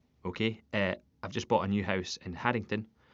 Edinburgh (Scottish) English